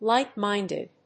アクセントlíght‐mínded
• enPR: lītʹmīn'd(ĭ)d